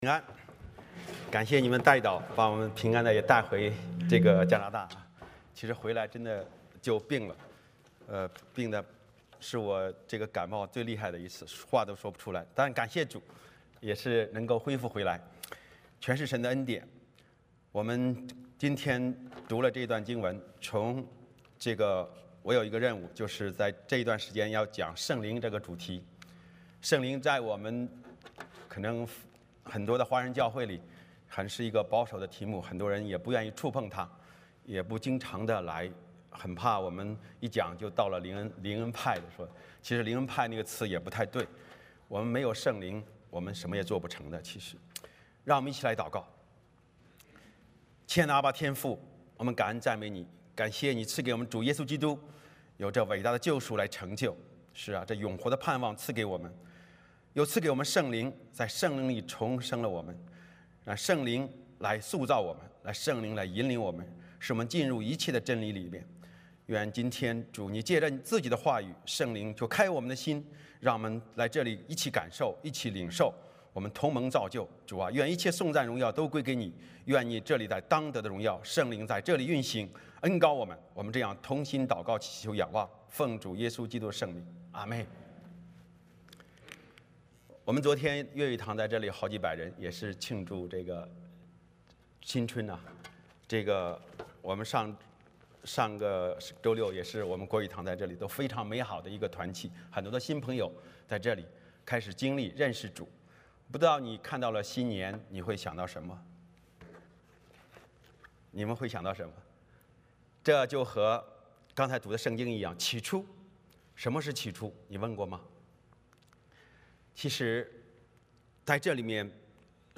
欢迎大家加入我们国语主日崇拜。
约翰福音4:24 Service Type: 主日崇拜 欢迎大家加入我们国语主日崇拜。